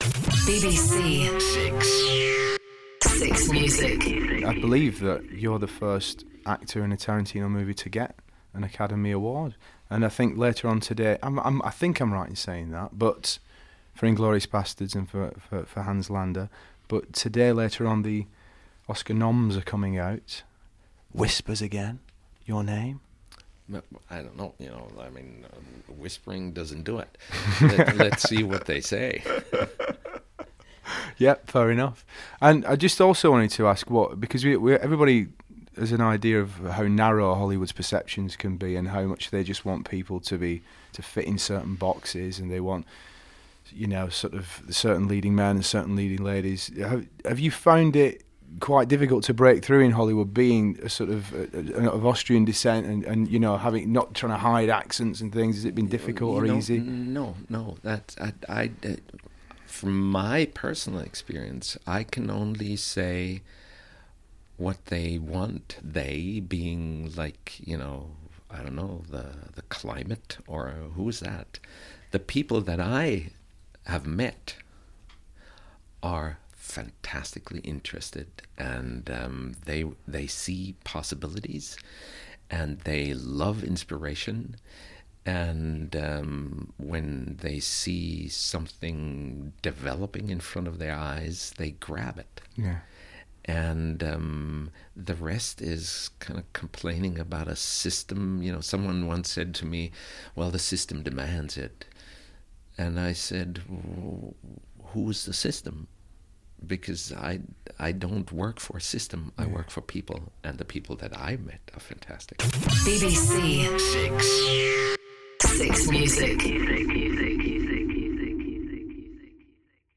Oscar nominated Christoph Waltz on the 6Music Shaun Keaveny Breakfast Show
Shaun very, very recently spoke to Christoph Waltz minutes before his Oscar nomination for best supporting actor in Django Unchained was announced.